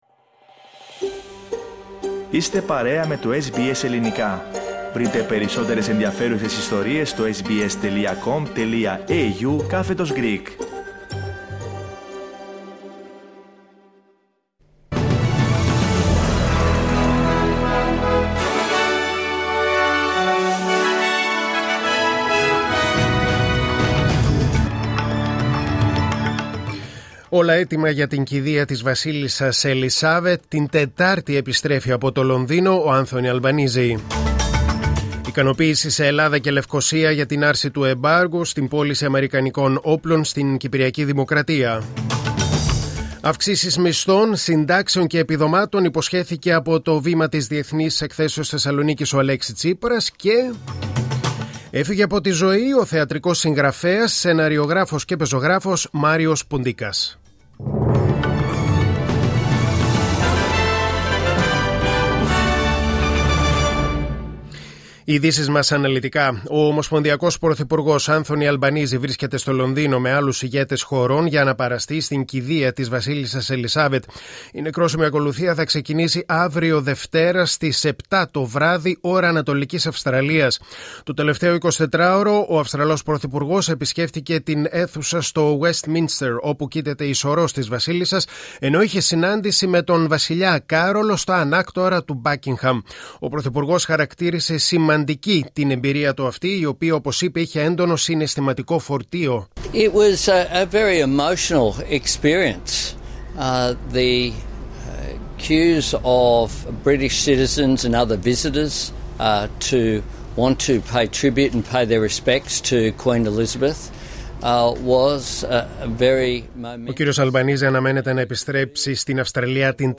News in Greek: Sunday 18.9.2022